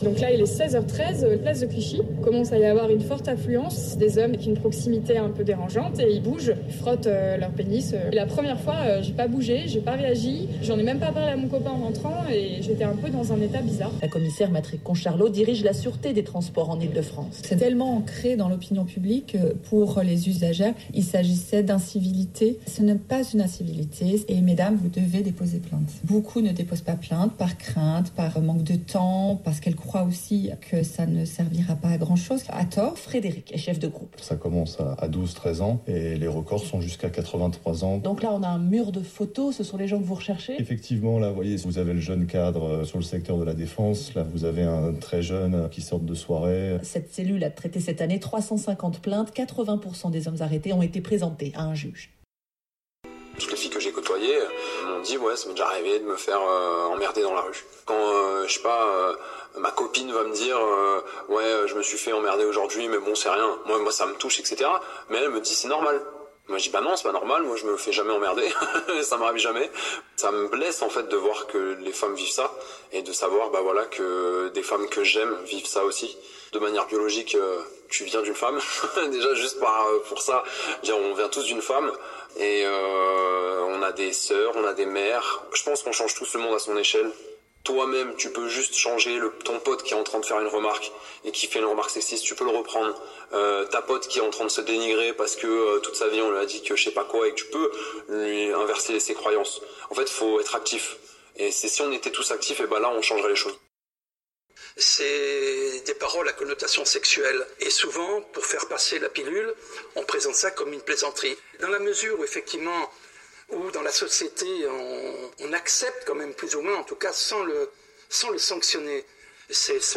Voici un petit montage d’un reportage entendu à la radio il y a quelque temps et de certains de ces témoignages.